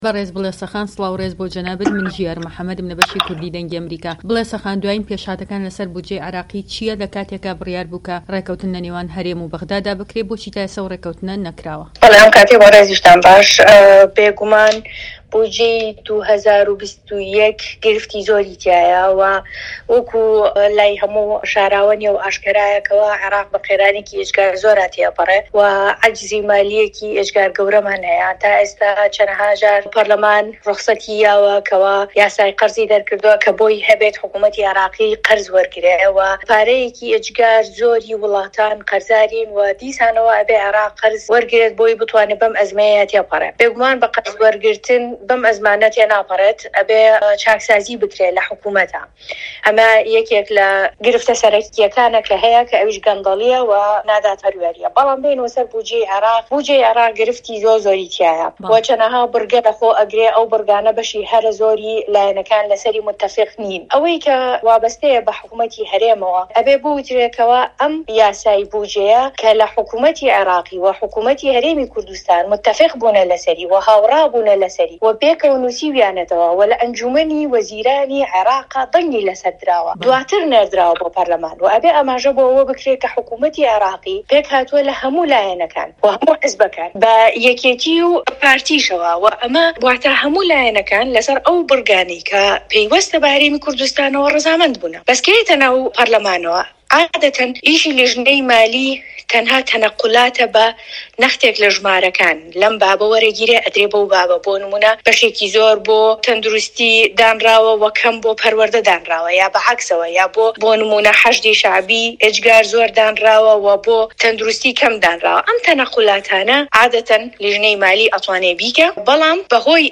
دەقی وتووێژەکەی